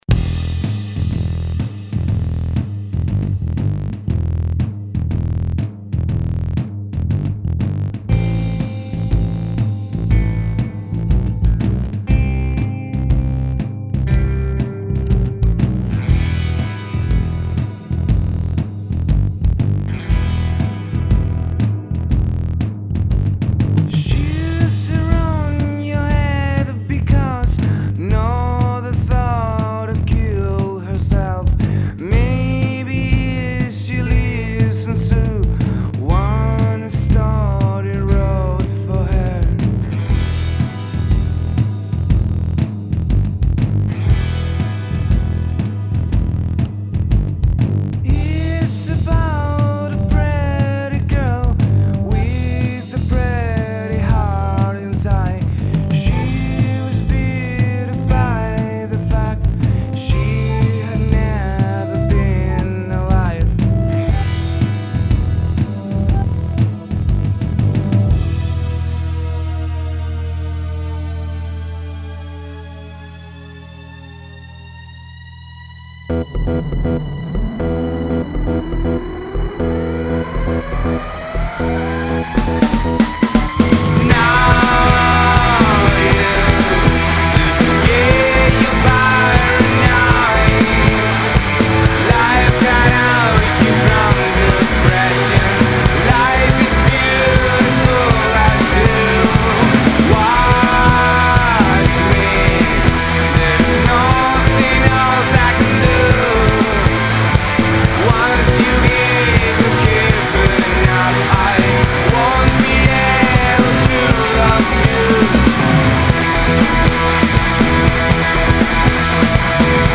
Estilo: Pop